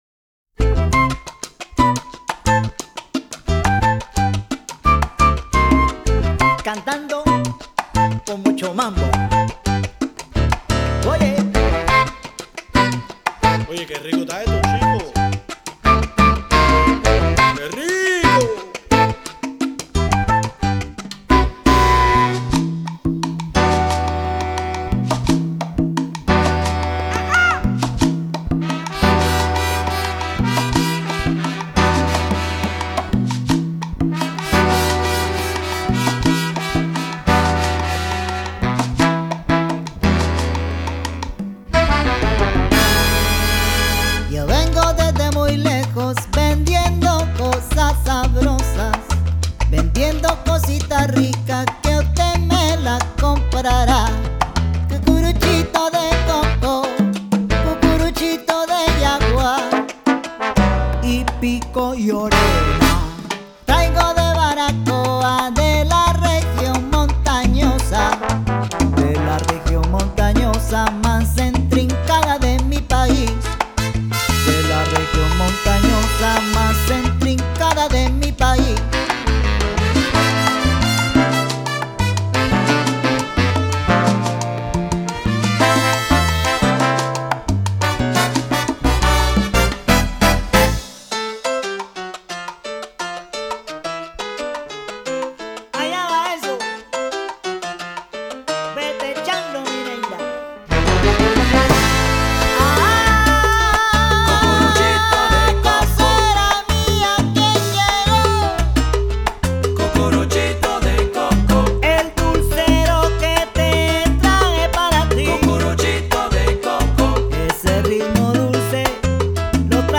mixing in elements of funk and jazz to create a unique edge.